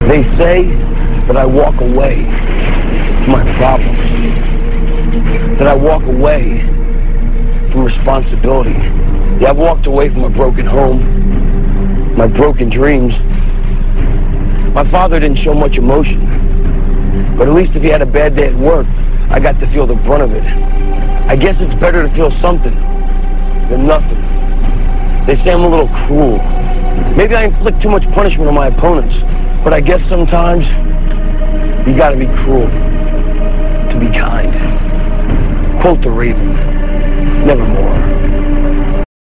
- This speech comes from a video promo on Nitro - [6.22.98]. Raven talks about his childhood & how sometimes you have to be cruel to be kind.